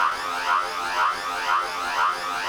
Sci-Fi Sounds
Alarm 3 Loop.wav